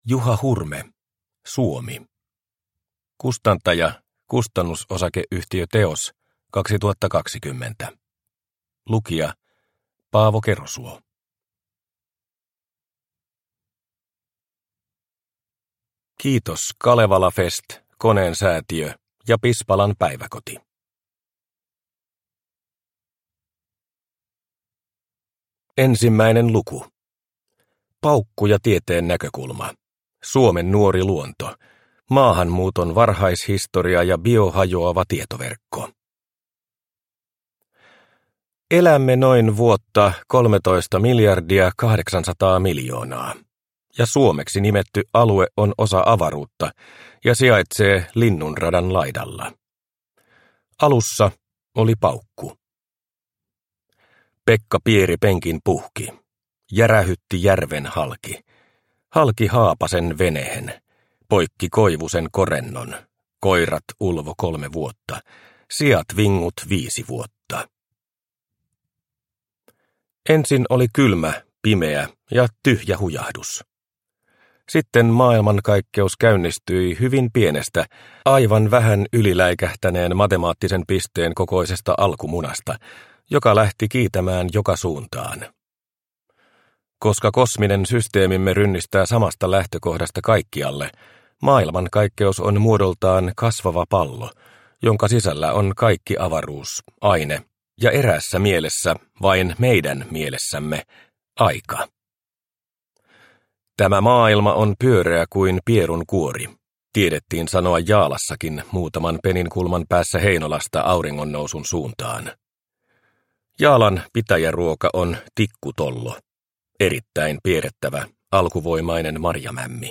Suomi – Ljudbok – Laddas ner